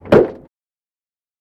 Cessna Door Close